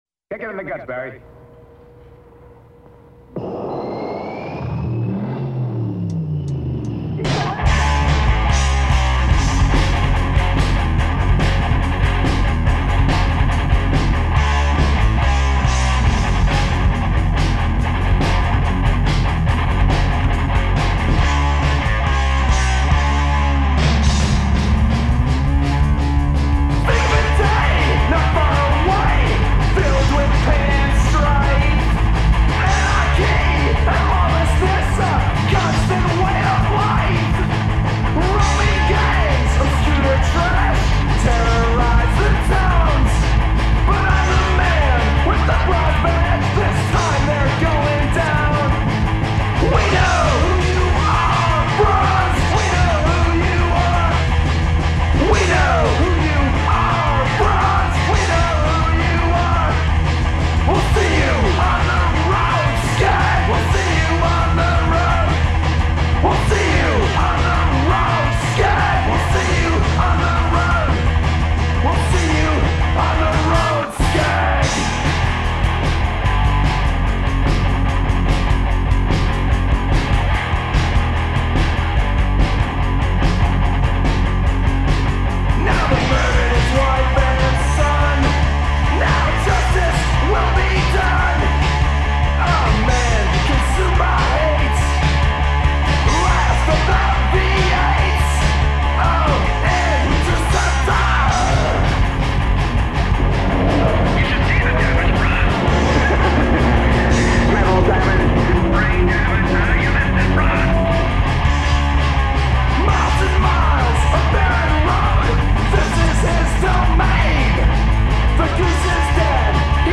Vocals
Drums
Bass
Guitar
Filed under: Punk